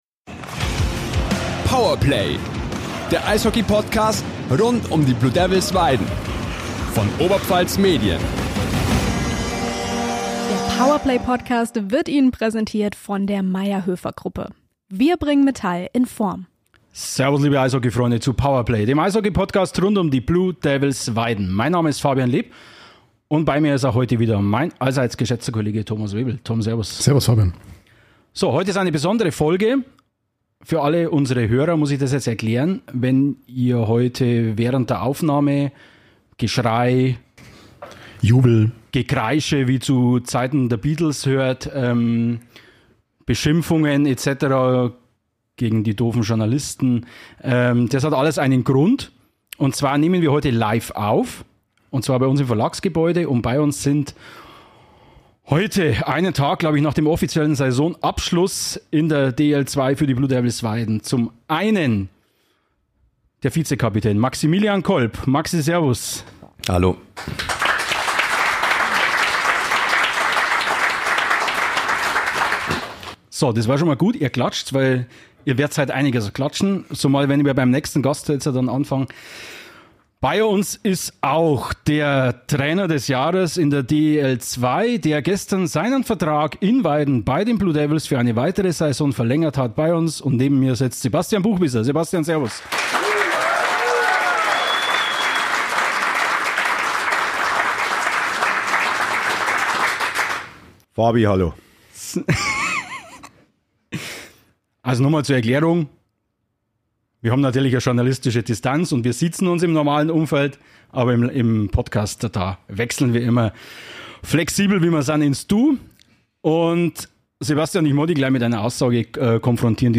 Powerplay live